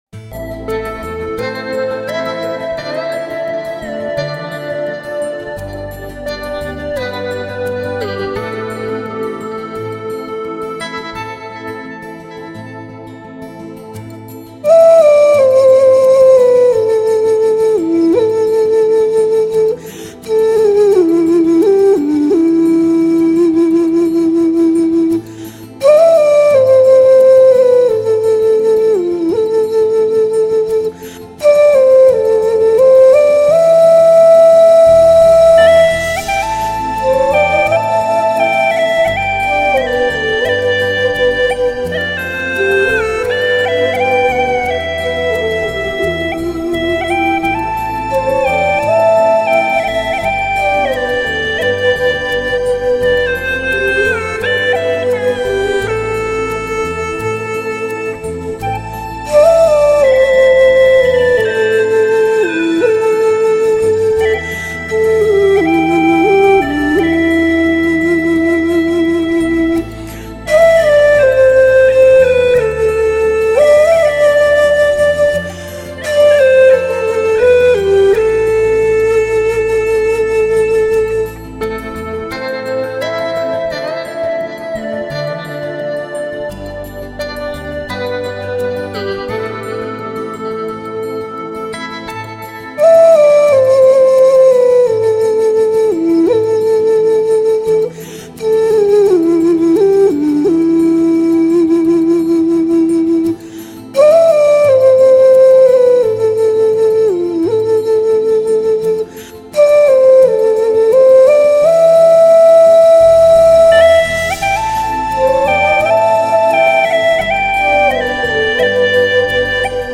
葫芦丝&埙